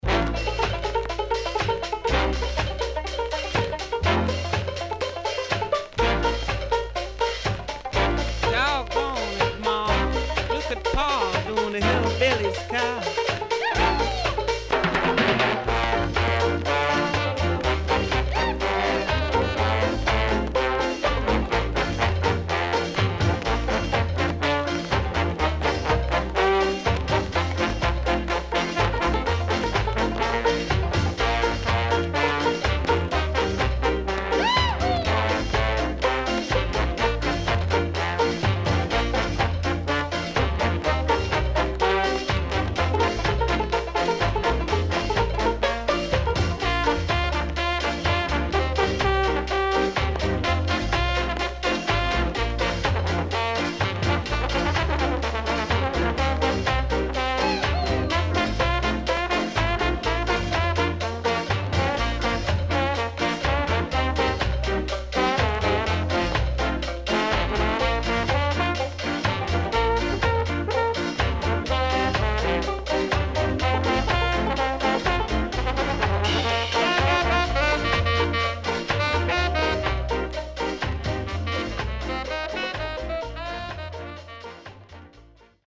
HOME > SKA / ROCKSTEADY  >  SKA  >  INST 60's
SIDE A:所々チリノイズがあり、少しプチノイズ入ります。